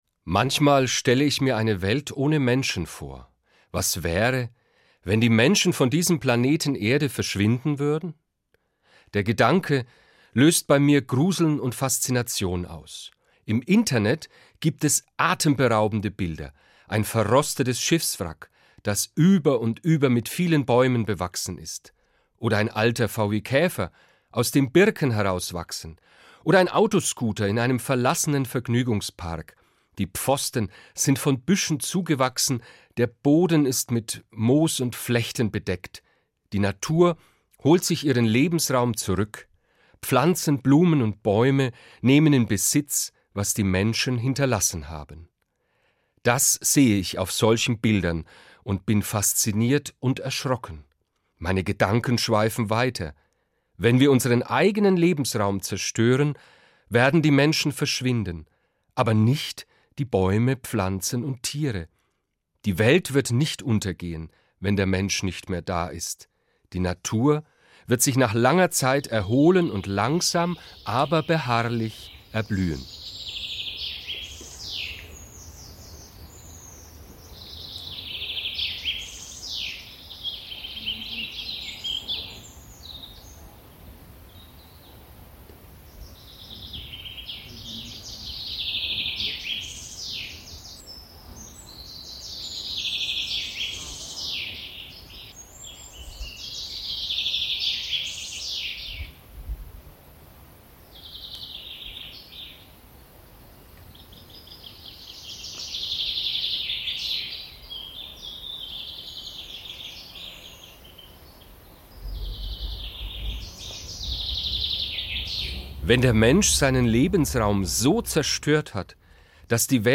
Musik 1: Naturgeräusche / Vogelgezwitscher